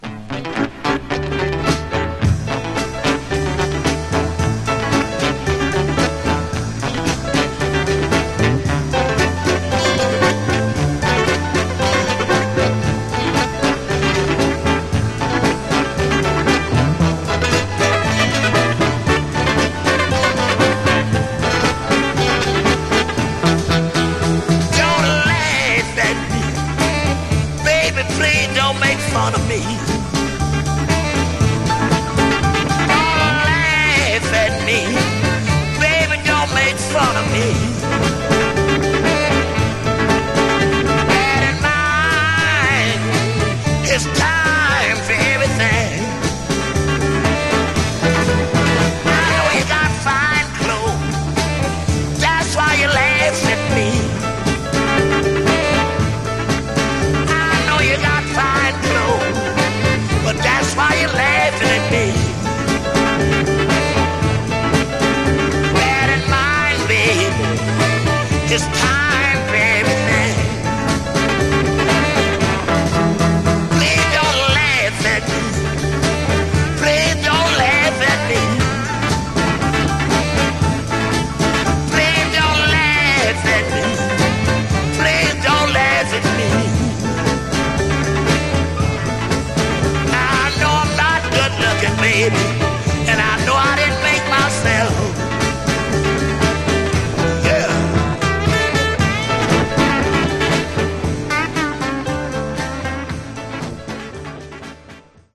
Genre: RnB
With a rhythm section that just won't quit
howls at his woman, pleading with menace